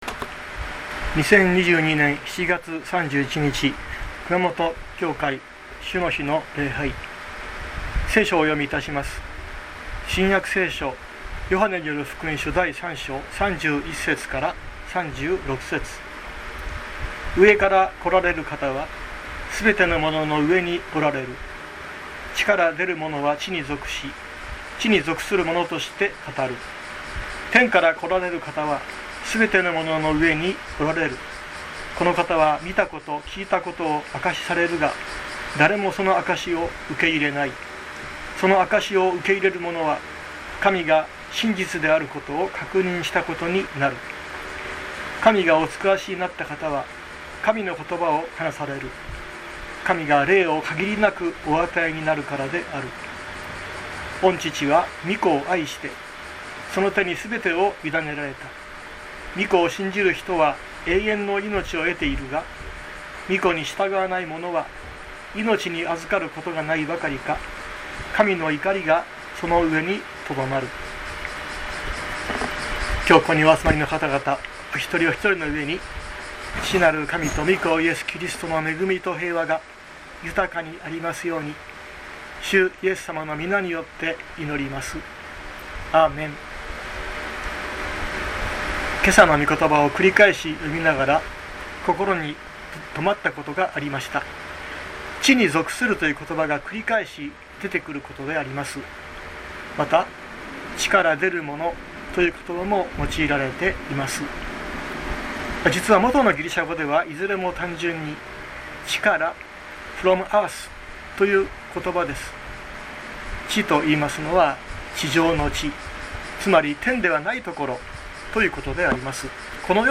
2022年07月31日朝の礼拝「三位一体の神」熊本教会
熊本教会。説教アーカイブ。